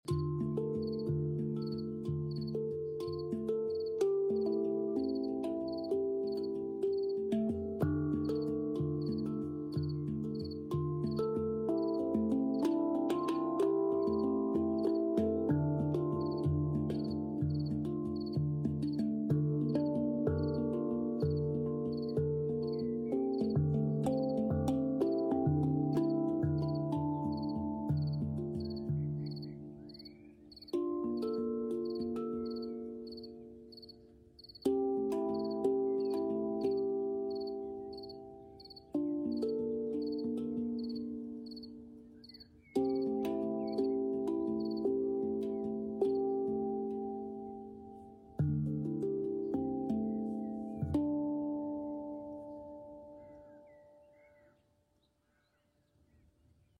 Entspannte Abendimpro Con Grillo😗 Mit Sound Effects Free Download